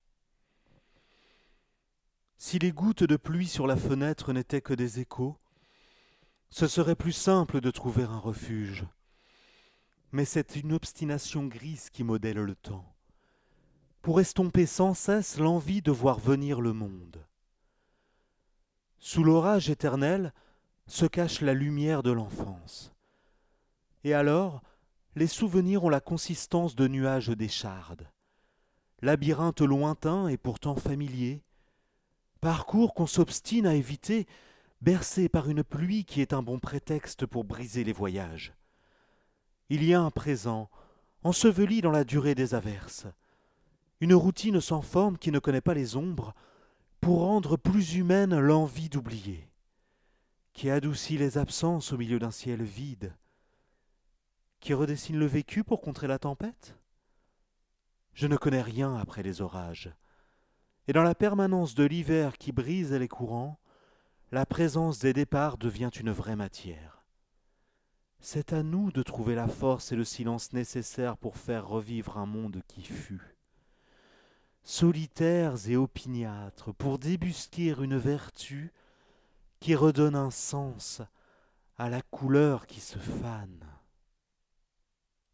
Lecture d'un poème publié dans la revue "Dissonances"
- Baryton-basse